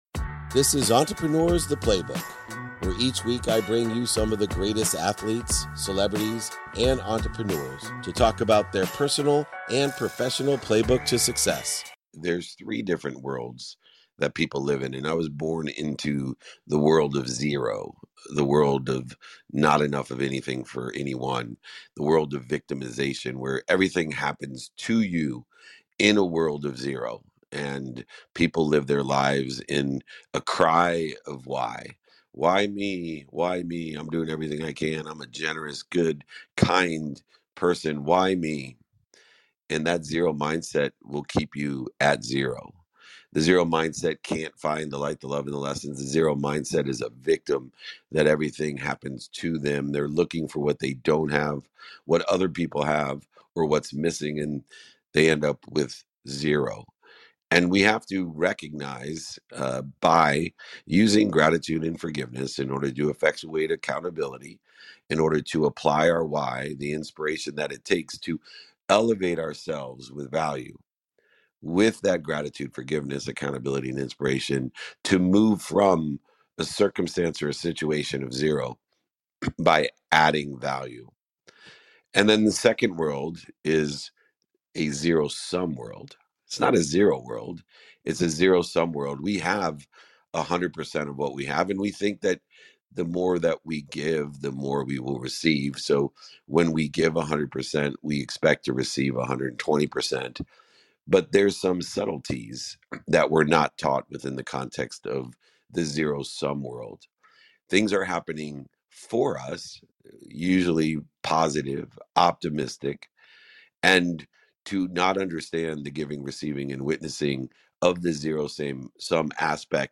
In today's episode, I take questions from around the room, addressing how to shift from scarcity to abundance, highlighting the power of gratitude, forgiveness, and accountability.